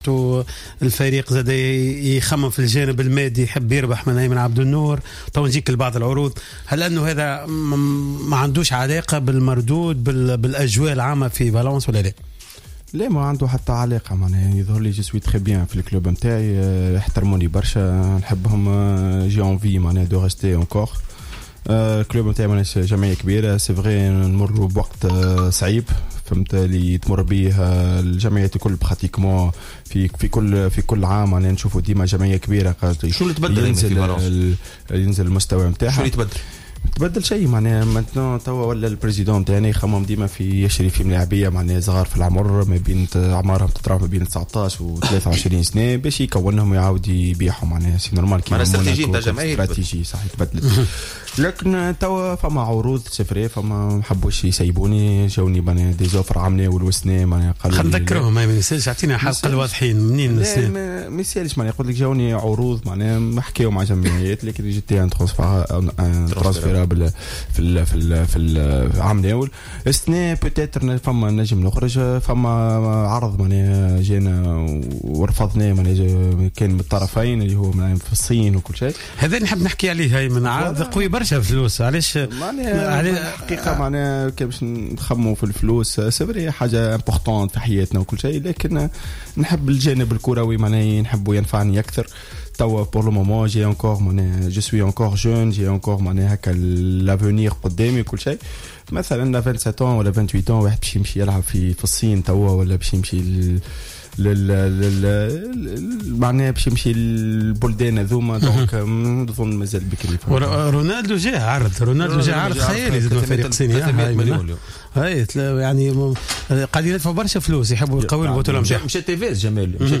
تحدث لاعب فالنسيا الإسباني أيمن عبد النور ضيف برنامج "قوول" عن العروض التي وصلته من عدة فرق إنجليزية و خاصة العرض الصيني الذي يعد الأضخم من الناحية المادية .